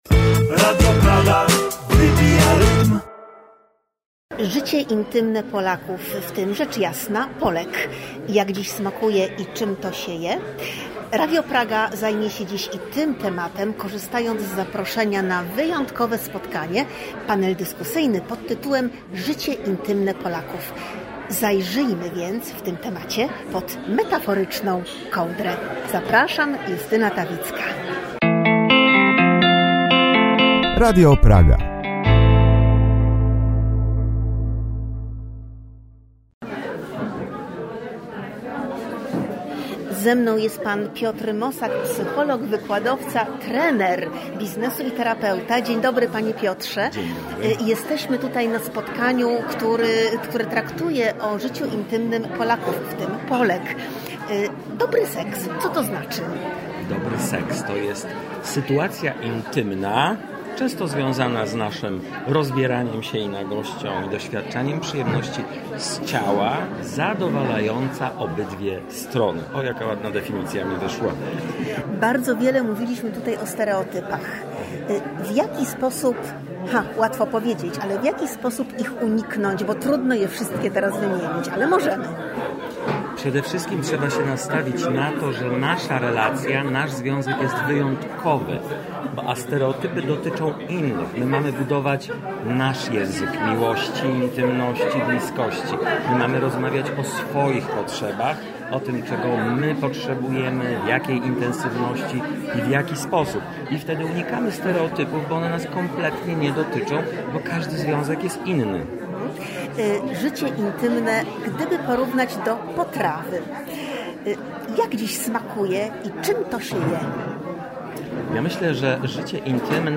Radio Praga 12 lutego gościło na szczególnym wydarzeniu, w Primitivo Kuchnia & Wino połączonym z bardzo ożywionym panelem dyskusyjnym pt. „Życie intymne Polaków”.